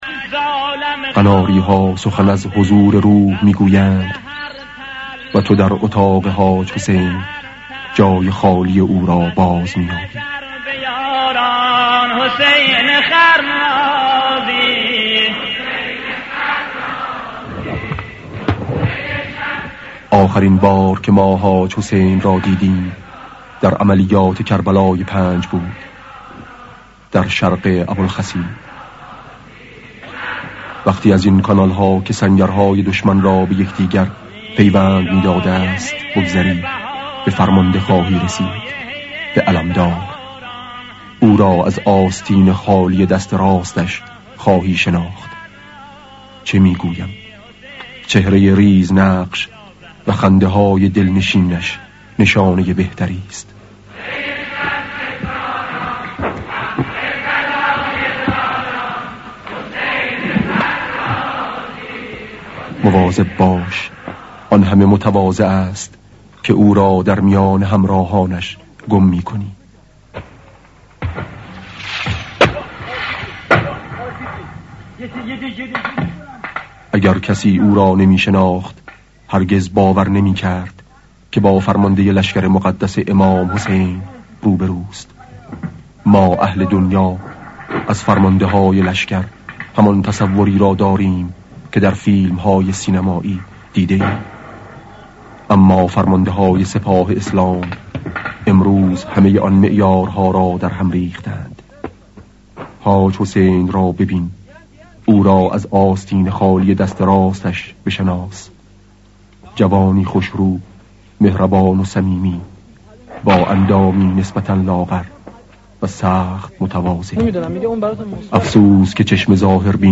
صدای شهید آوینی/ وقتی از این سنگرها بگذری به فرمانده میرسی، به علمدار!